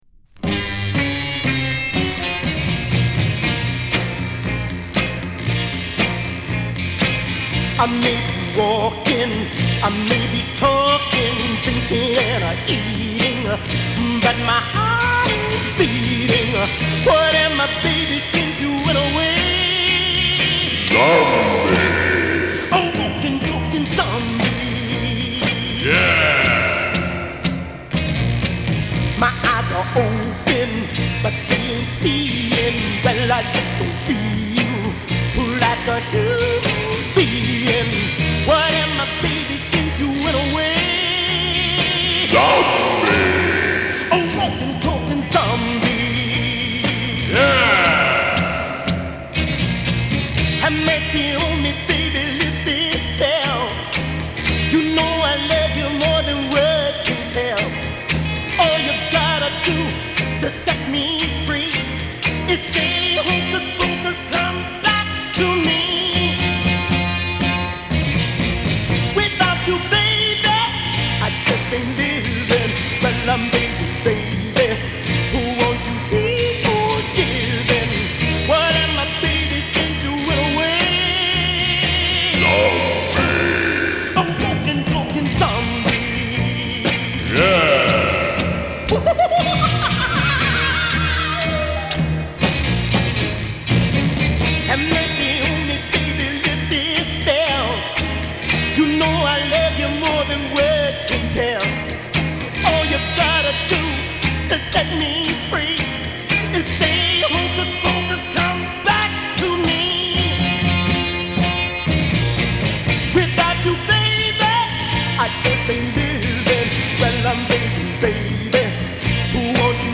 中身はファズの効いたギターを配したエクセレント・ホラー〜ガレージ・ロッカー。